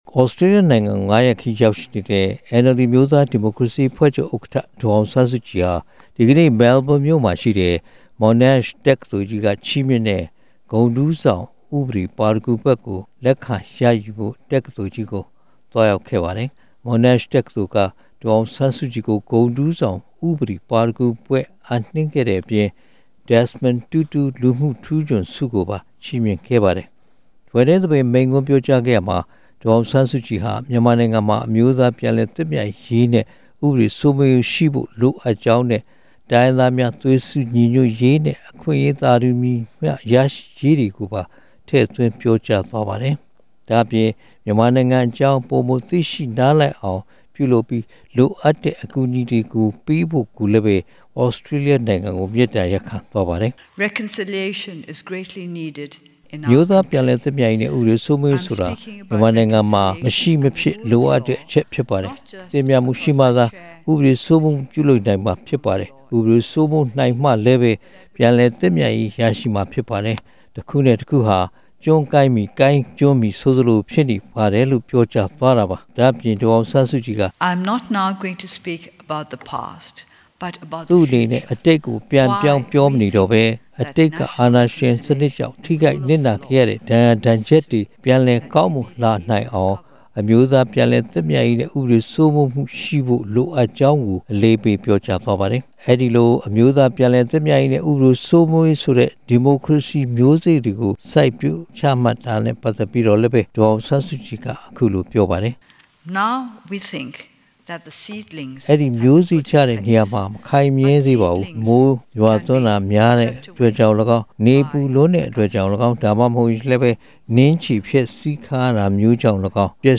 ဒေါ်အောင်ဆန်းစုကြည်က မြန်မာနိုင်ငံရဲ့ အမျိုးသား ပြန်လည်သင့်မြတ်ရေး၊ တရားဥပဒေ စိုးမိုးရေးတို့နဲ့ ပတ်သက်လို့ ဘွဲ့နှင်းသဘင်မိန့်ခွန်းမှာ အခုလို ထည့်သွင်းပြောကြားခဲ့ပါတယ်။